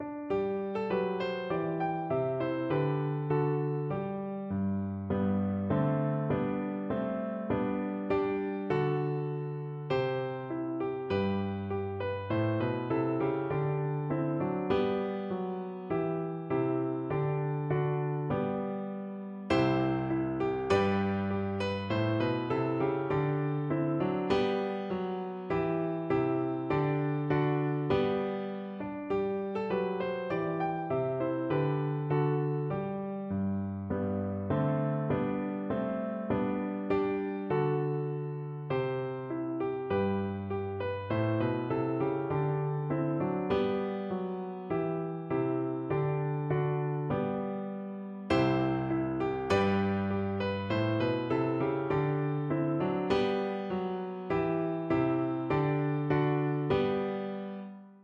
Traditional Trad. A-Roving (English sea shanty) Soprano (Descant) Recorder version
Recorder
Allegro moderato (View more music marked Allegro)
2/4 (View more 2/4 Music)
G major (Sounding Pitch) (View more G major Music for Recorder )
Traditional (View more Traditional Recorder Music)